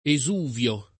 [ e @2 v L o ]